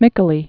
(mĭkə-lē)